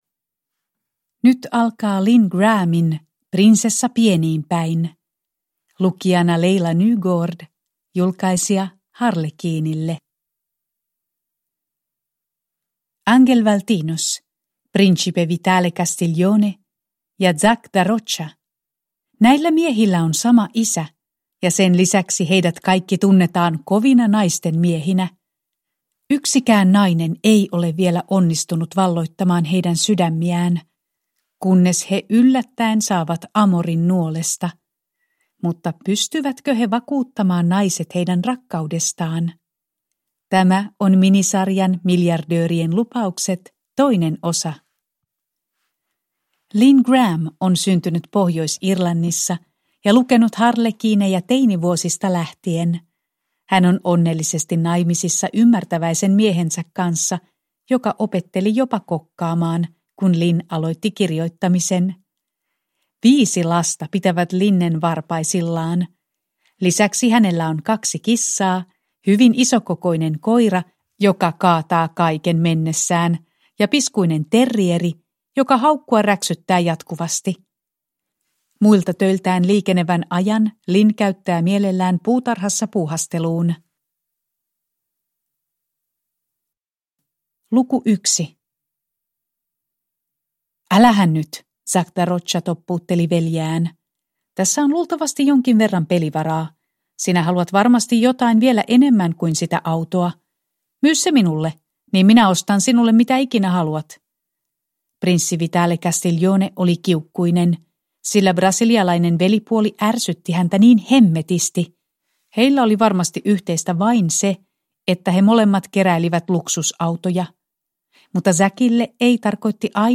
Prinsessa pieniin päin – Ljudbok – Laddas ner